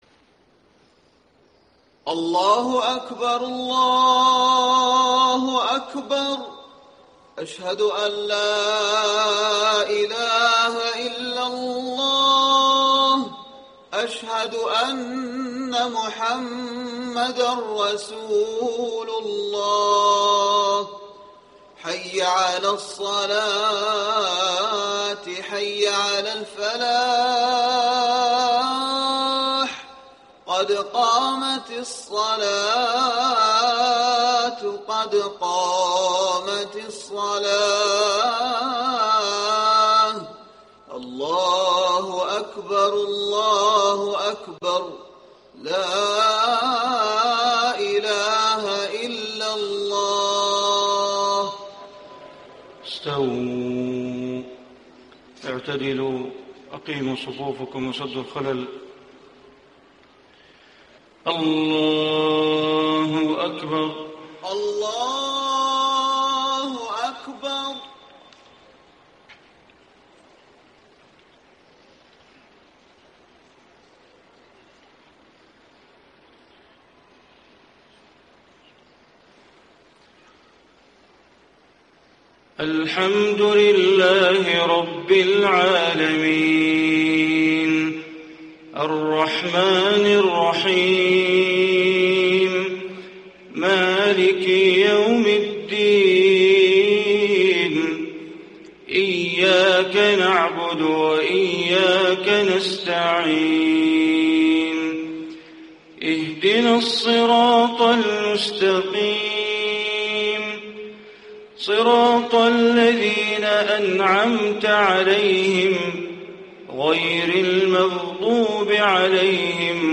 صلاة الفجر 9-2-1435 من سورة يوسف > 1435 🕋 > الفروض - تلاوات الحرمين